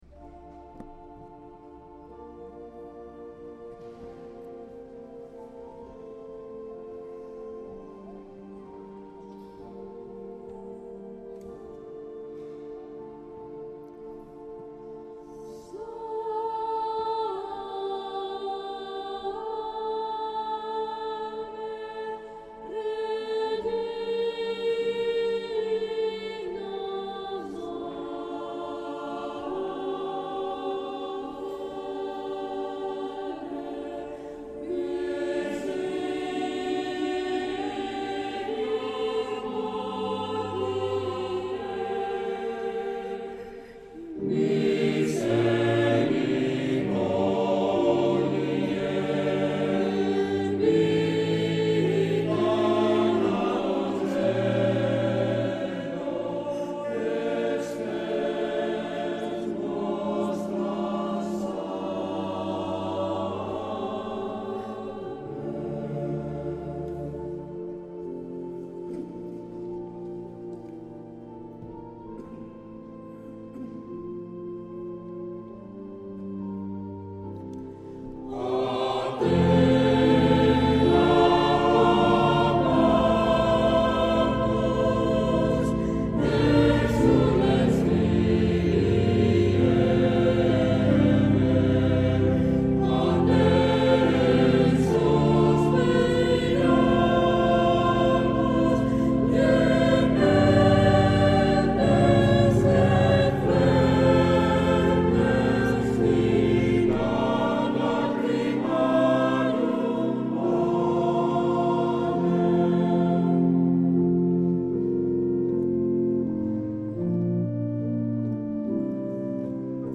(Txintxarri abesbatza)